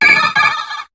Cri de Coquiperl dans Pokémon HOME.